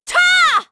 Seria-Vox_Attack4_kr.wav